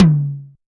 DX tom high.wav